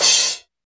Index of /90_sSampleCDs/Roland L-CDX-01/CYM_Crashes 1/CYM_Crsh Modules
CYM 15 CHO0A.wav